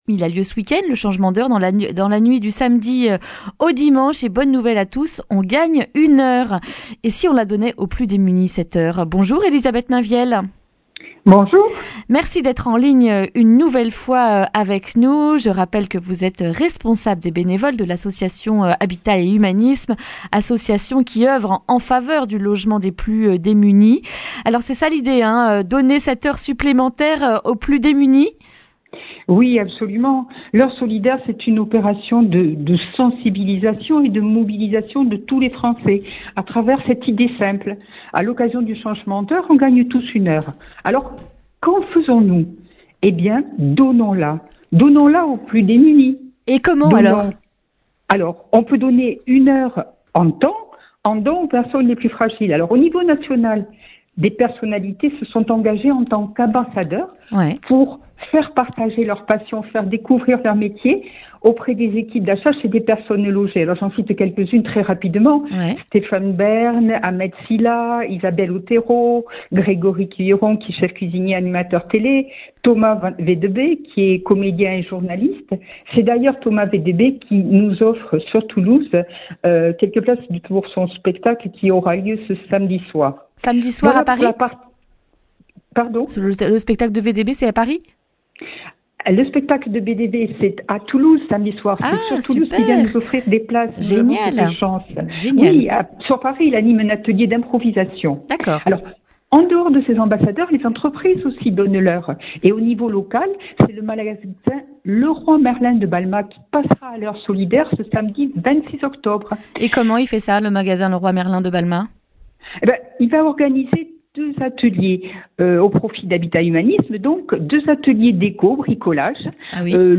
jeudi 24 octobre 2019 Le grand entretien Durée 11 min